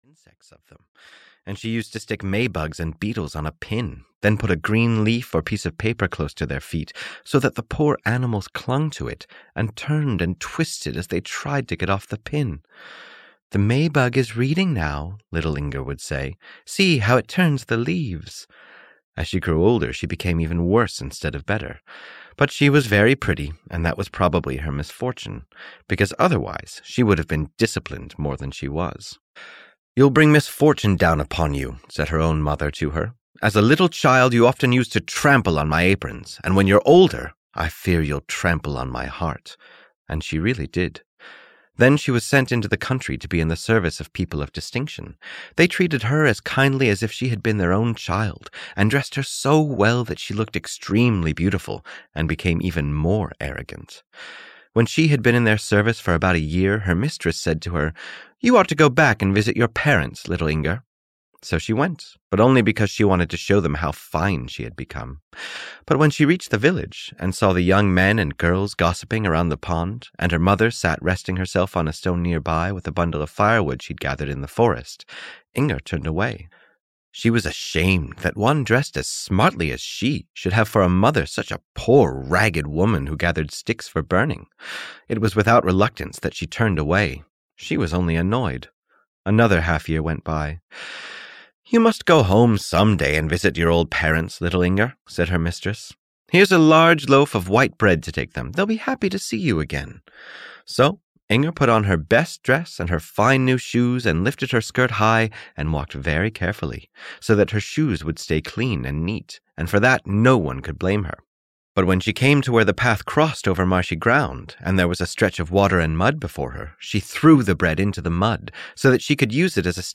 Audio knihaThe Girl Who Trod on the Loaf (EN)
Ukázka z knihy